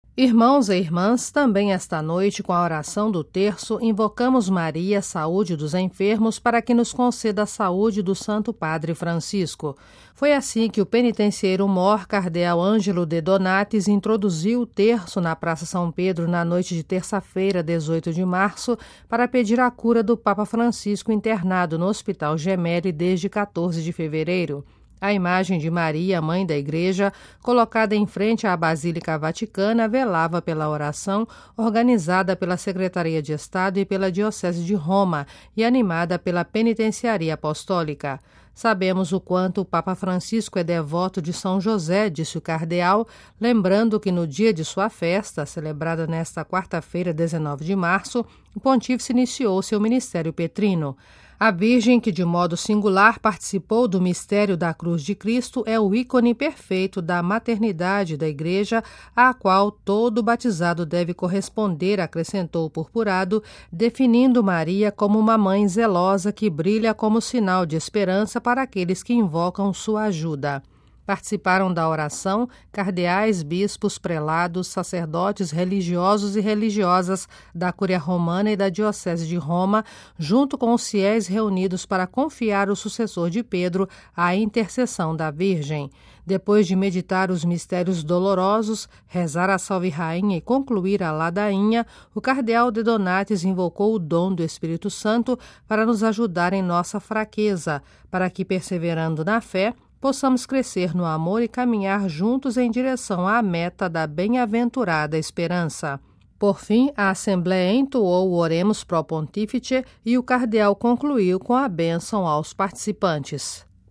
Na Praça São Pedro, o Terço pela saúde do Papa foi animado pela Penitenciaria Apostólica. O penitencieiro-mor recordou a devoção de Francisco a São José na véspera da sua festa.